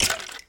Sound / Minecraft / mob / skeleton / hurt3.ogg
hurt3.ogg